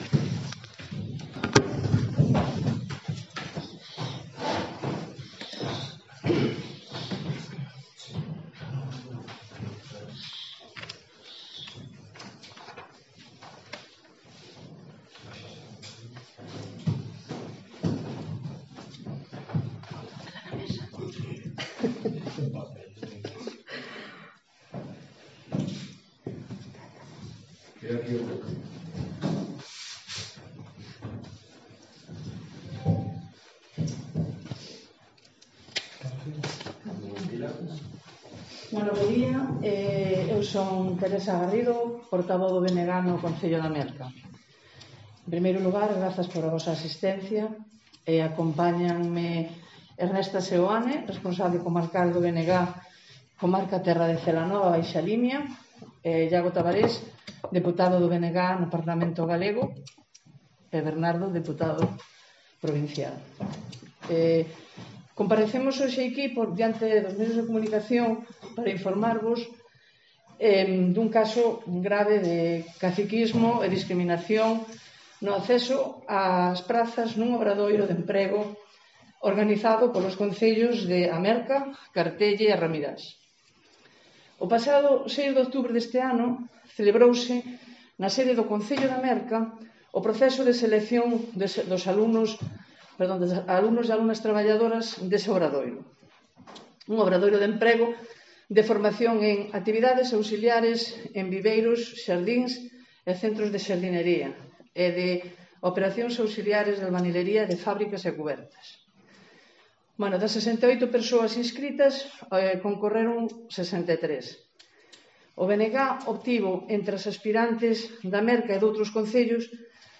Iago Tabarés, deputado do BNG
compareceron ante os medios de comunicación